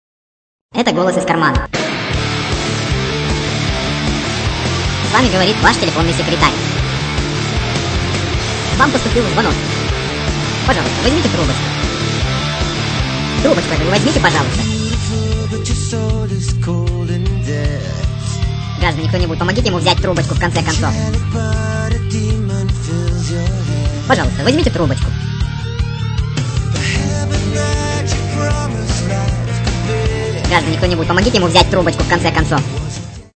Голос из кармана и музыка из фильма